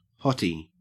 Ääntäminen
IPA : /ˈhɒ.ti/